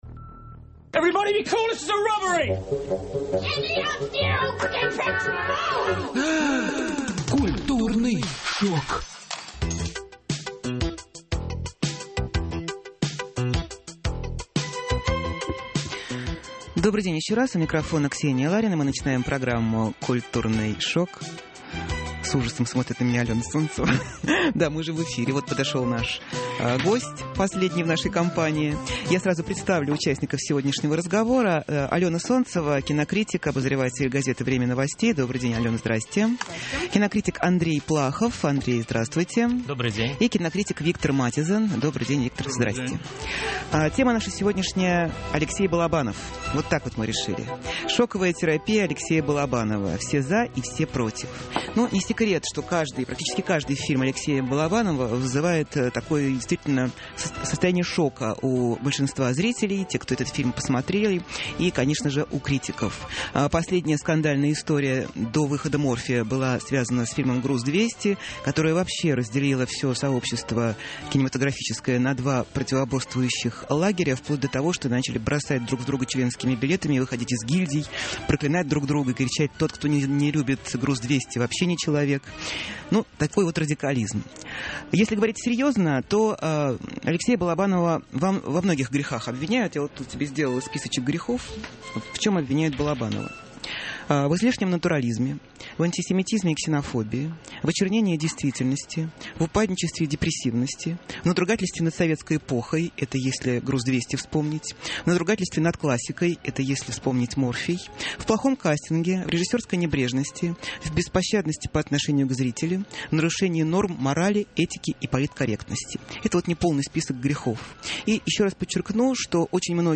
К. ЛАРИНА: Кинокритик Андрей Плахов.